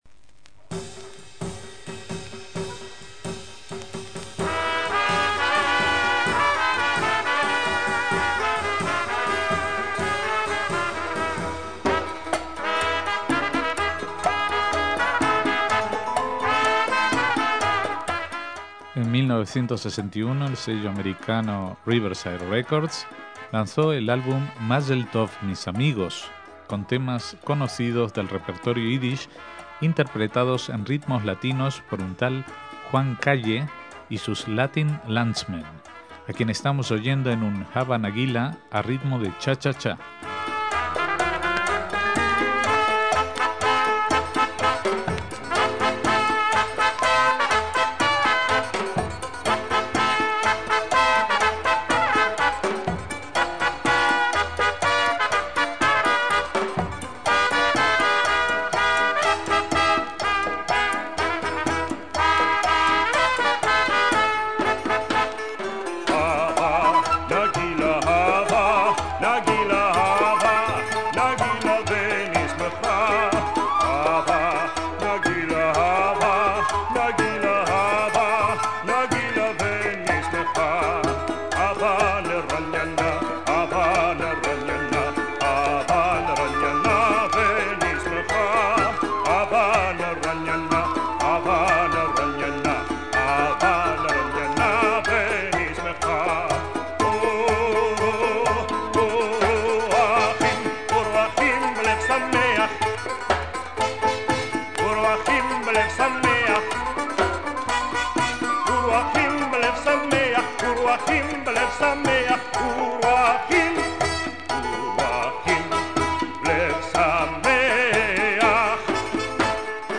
Fusionando la música del este de Europa y los ritmos latinos
banjo